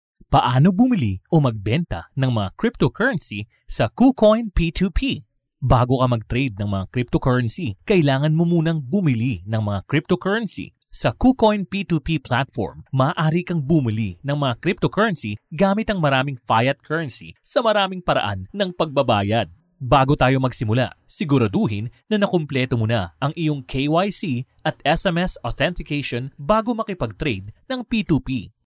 电台主播